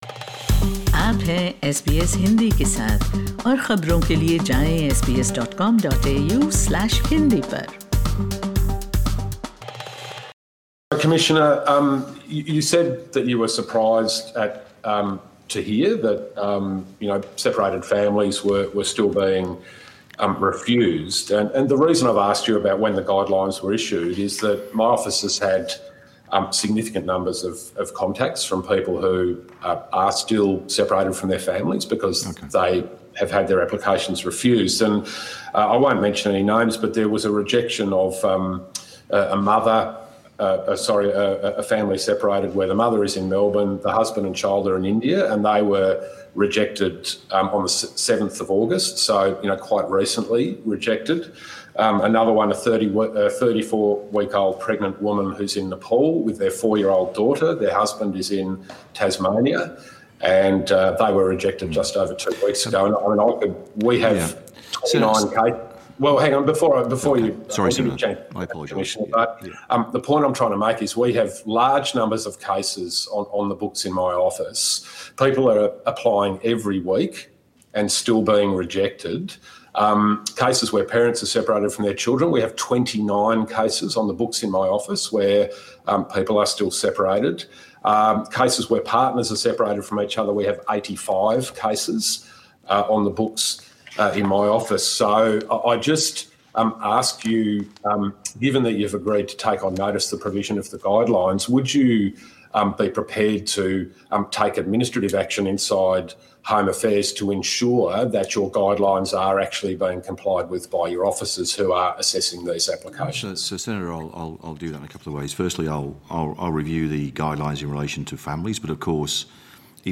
Australian Border Force Commissioner Michael Outram speaks during a Senate inquiry.